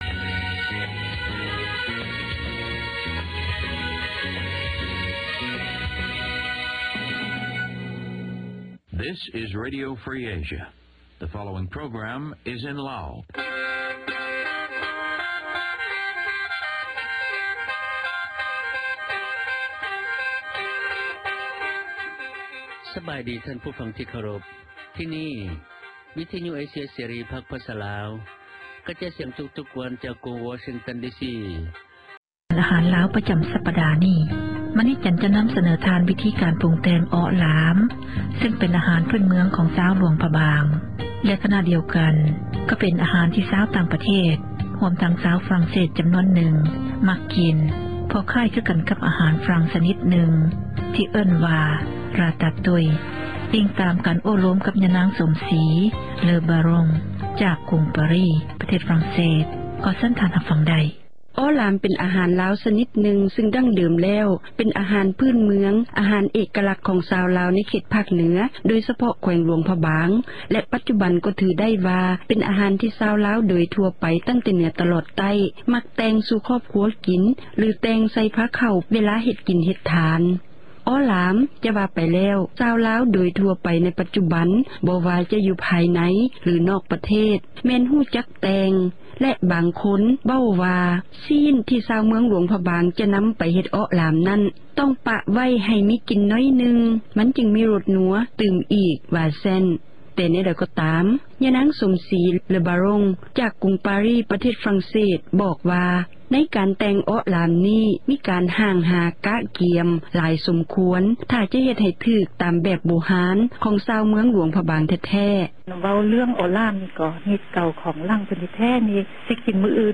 O Lam Luangprabang (Interview